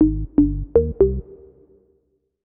Melodic Up.wav